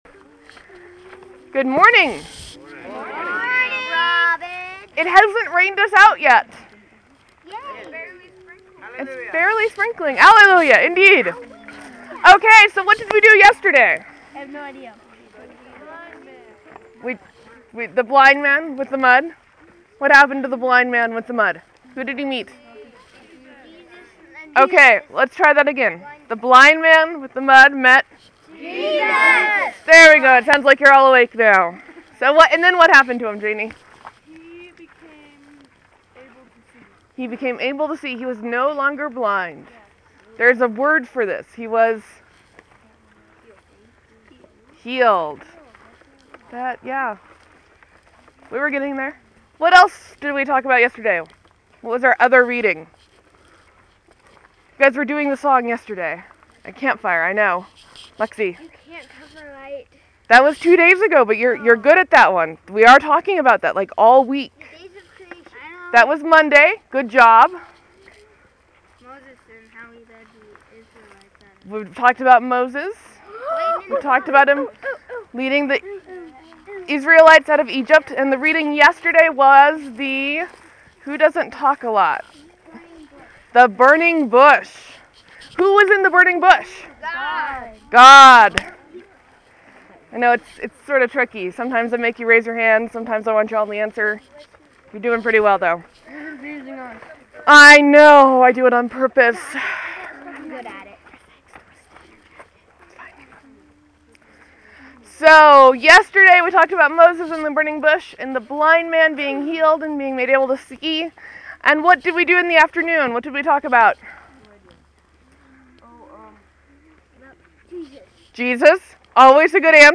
Camp, Sermon, , Leave a comment
Standard Camp disclaimers: lots of dialogue, wind, water (the lake is behind me), and it tried to rain on us today. I do my best to make sure everything is picked up by the mic.